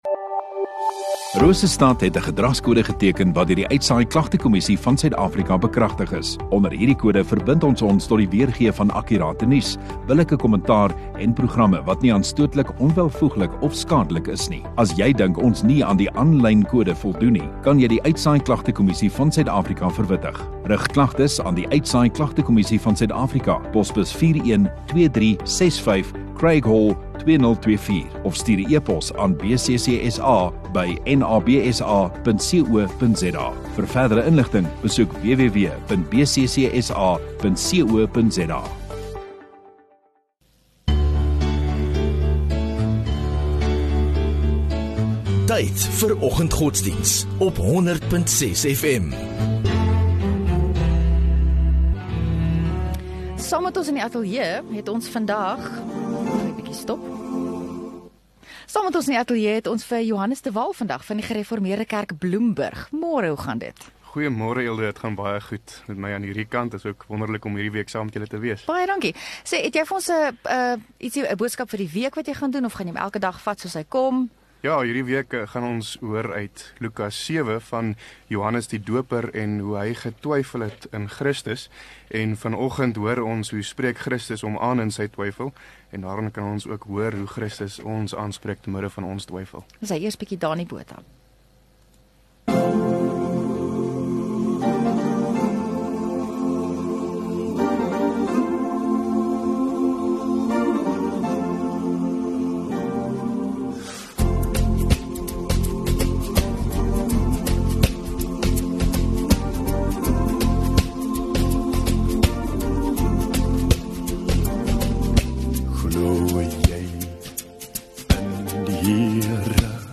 29 Jul Maandag Oggenddiens